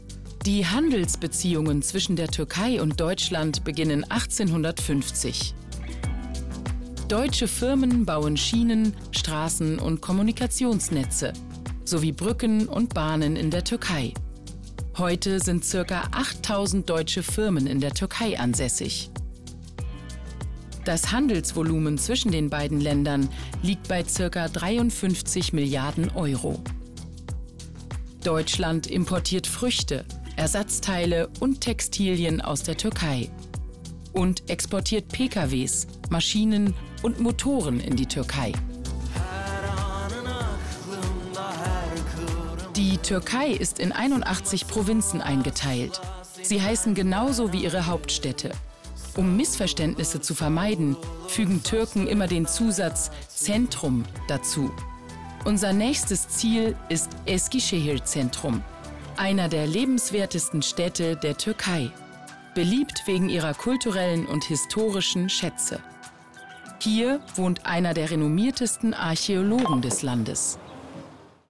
dunkel, sonor, souverän, markant, sehr variabel
Doku